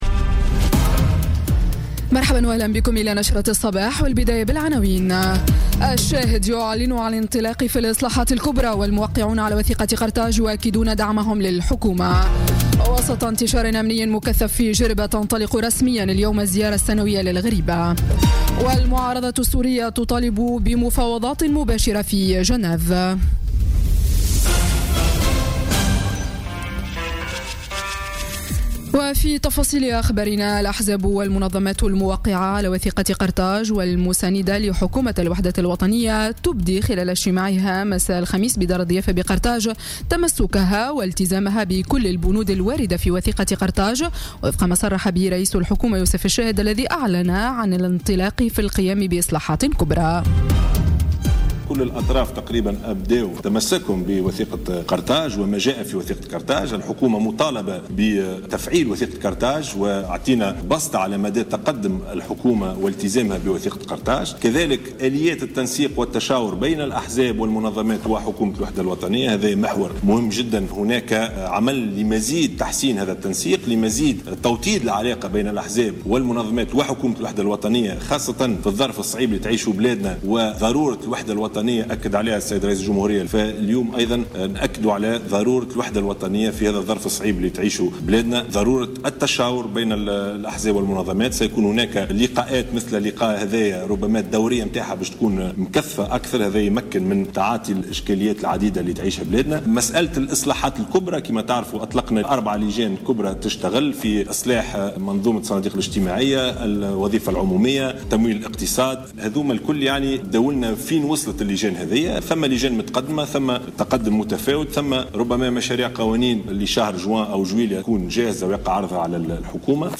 نشرة أخبار السابعة صباحا ليوم الجمعة 12 ماي 2017